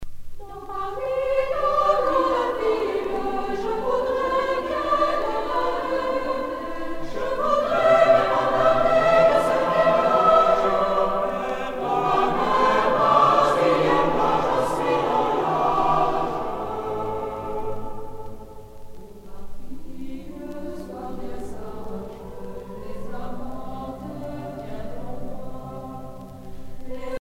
Franco-Allemande de Paris (chorale)
Pièce musicale éditée